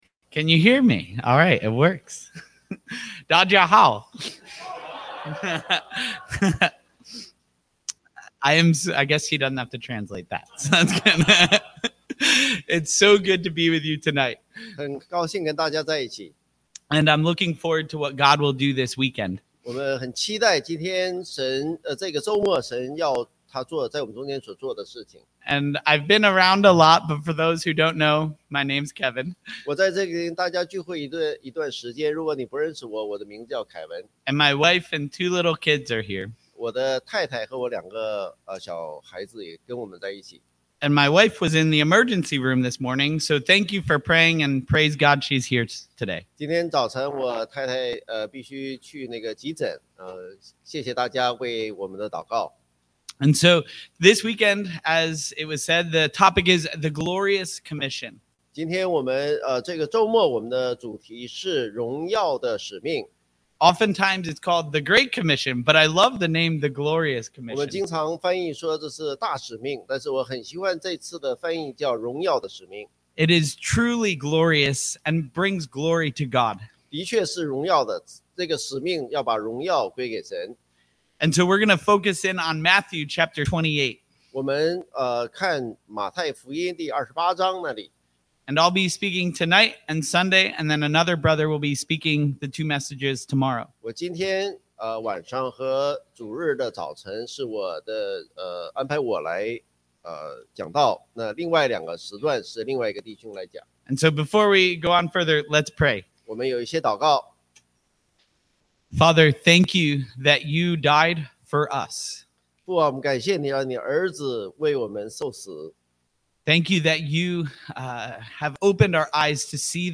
2025 Thanksgiving Retreat